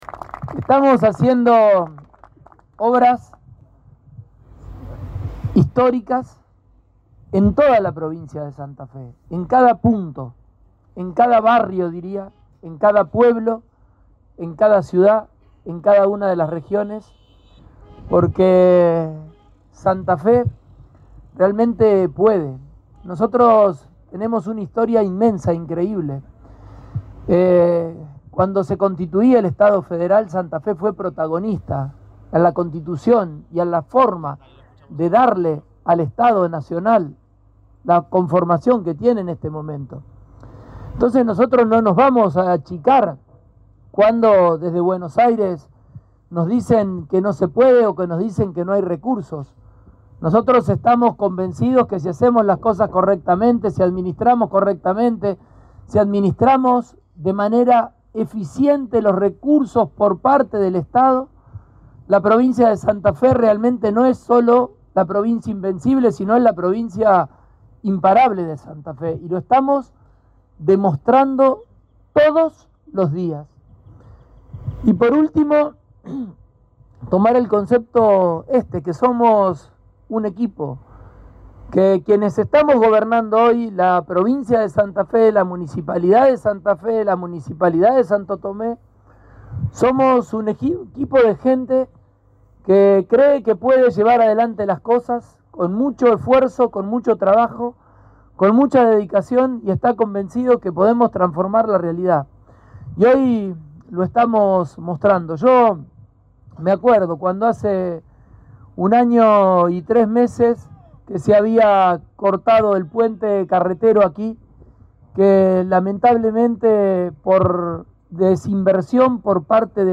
Lo dijo el gobernador en el inicio de la colocación de los pilotes de lo que será el nuevo puente entre Santa Fe y Santo Tomé.
Fragmentos de alocuciones de Pullaro y Enrico